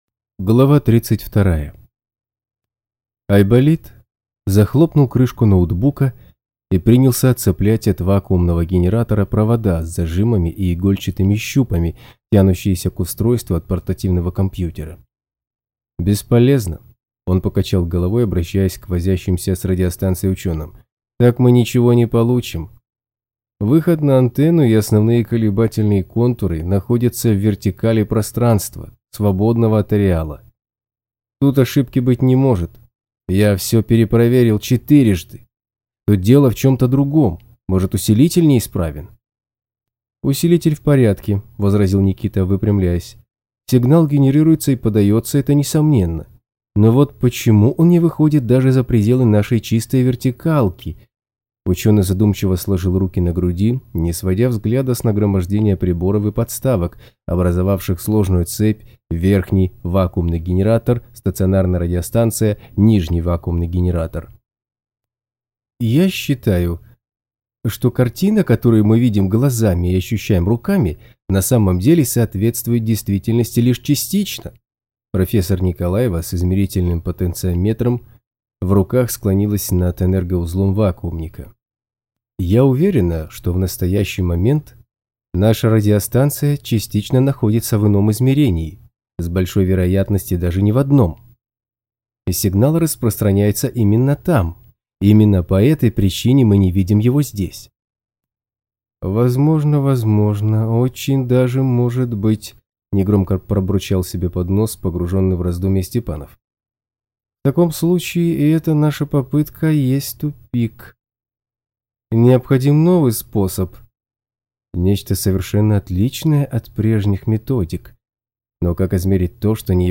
Аудиокнига Государство в государстве (Ареал 5)
Качество озвучивания довольно высокое.